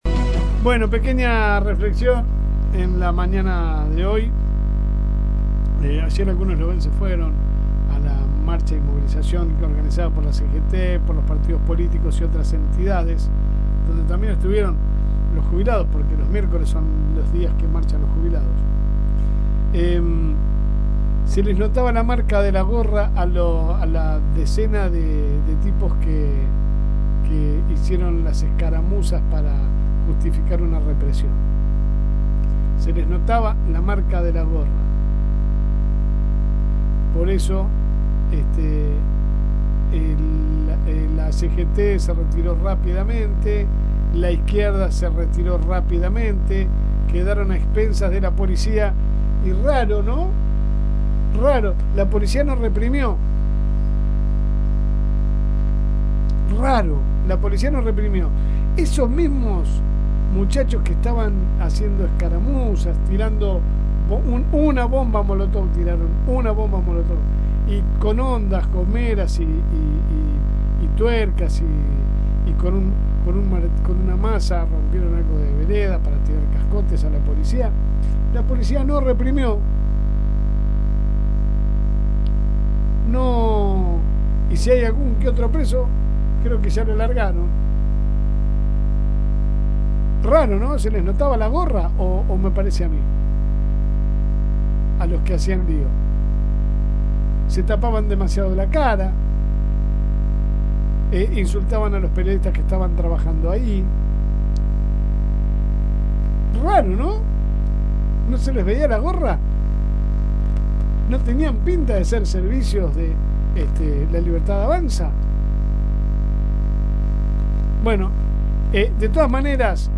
AUDIO. Jueves de editorial
La editorial a continuación: